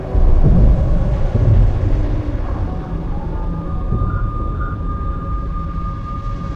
Alarm2_7.ogg